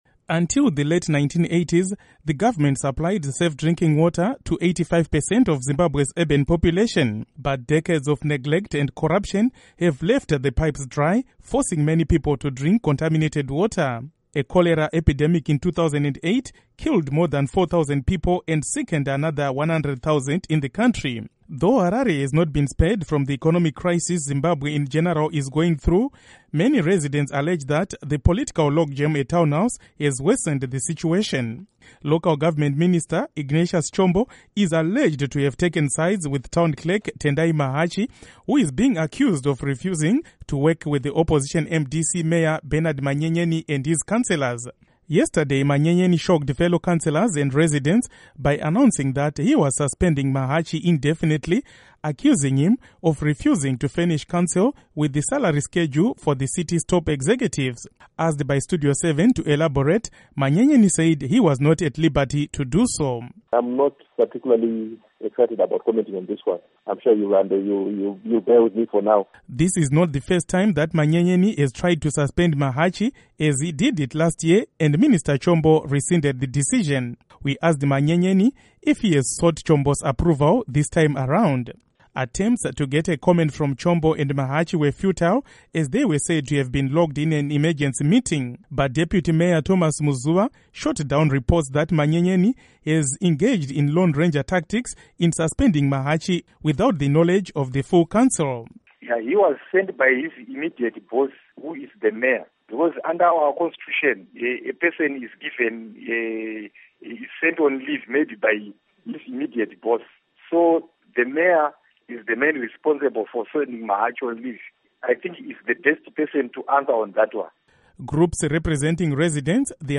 Report on Harare City Council